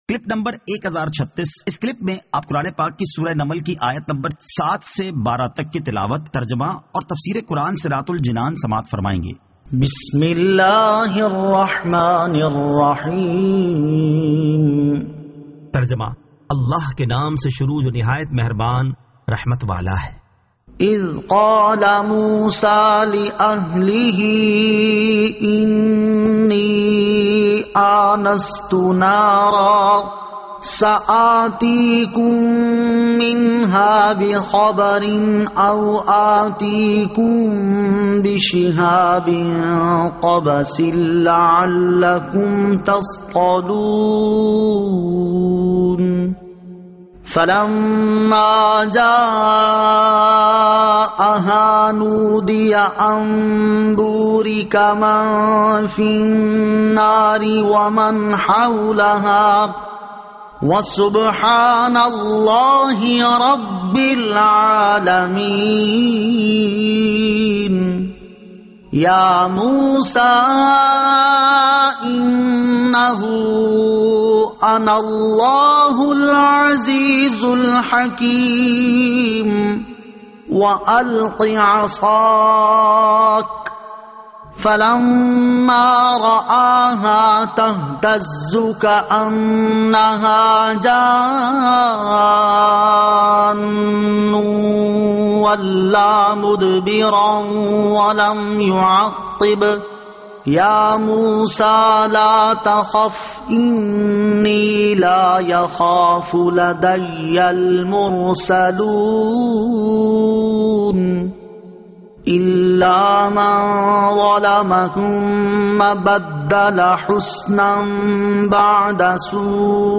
Surah An-Naml 07 To 12 Tilawat , Tarjama , Tafseer